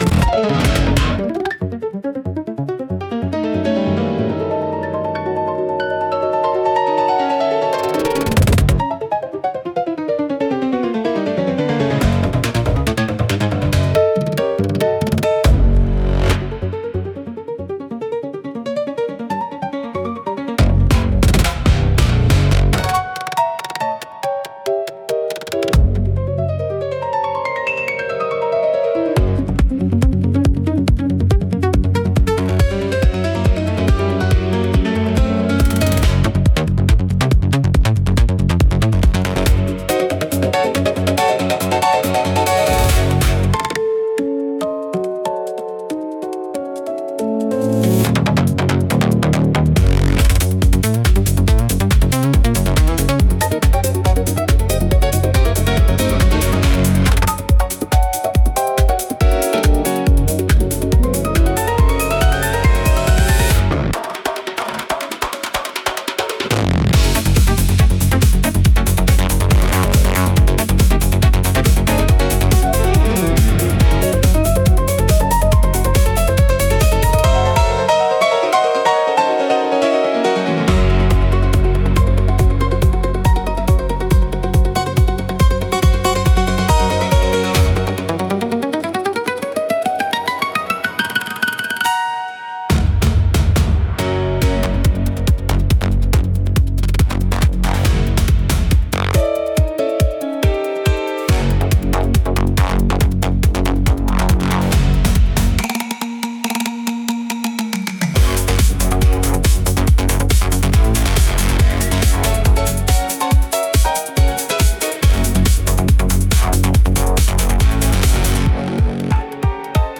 イメージ：インスト,ピアノ,エクスペリメンタル・ミュージック,グリッチ,アヴァンギャルド,緊迫感
インストゥルメンタル（instrumental）